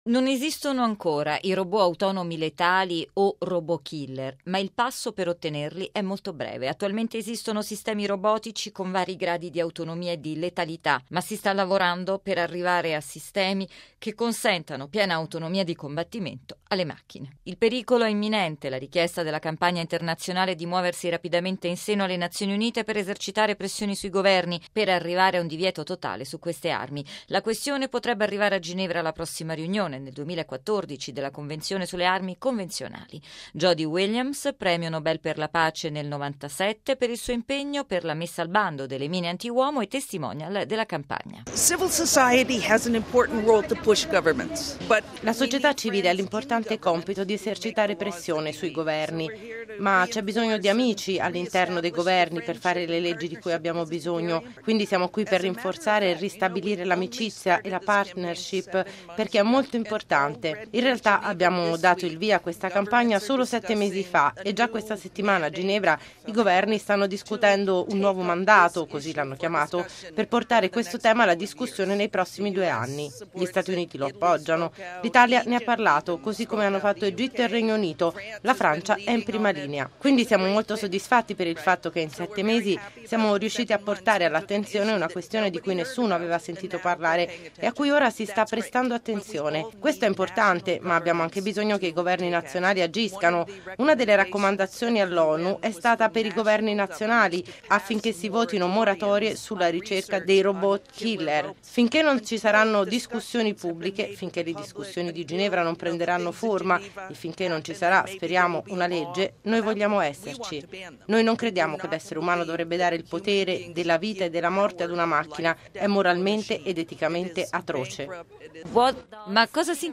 La Campagna internazionale “Stop killer Robots” chiede ai Paesi tutti di lavorare sui divieti nelle legislazioni nazionali così come su un divieto internazionale. Servizio